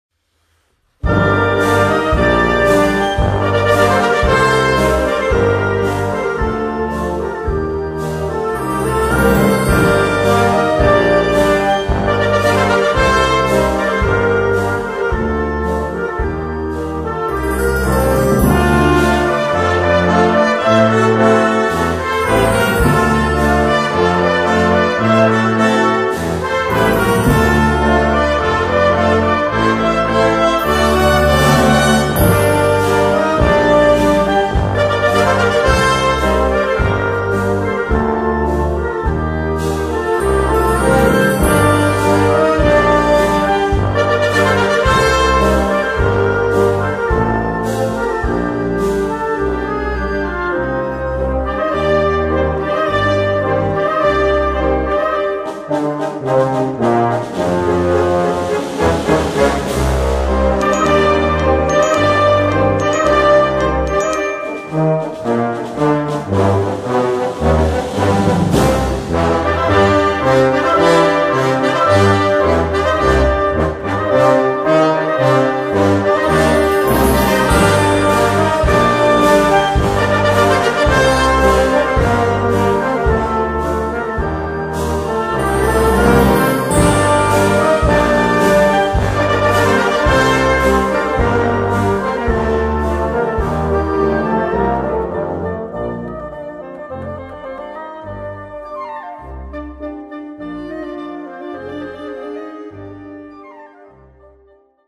Voicing: Concert Band - Blasorchester - Harmonie